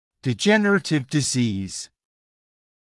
[dɪ’ʤenərətɪv dɪ’ziːz][ди’джэнэрэтив ди’зиːз]дегенеративное заболевание